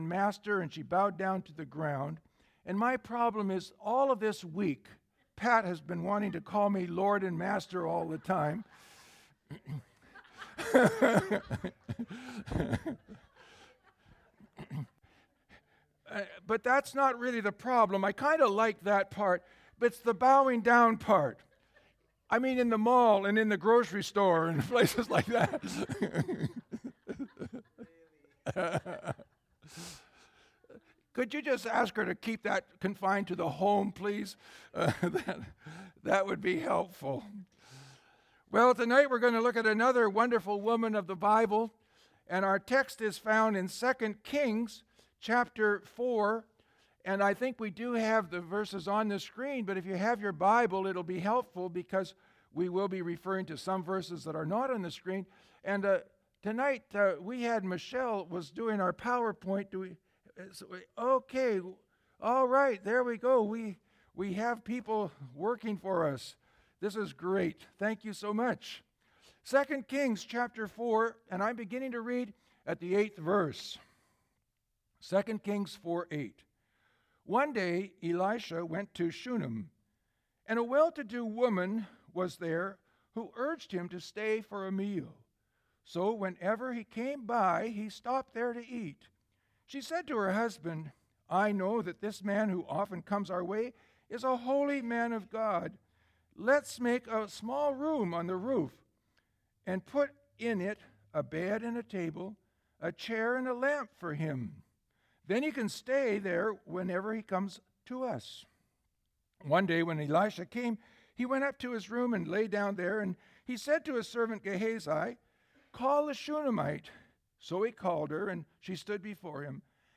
Sermons | Surrey Pentecostal Assembly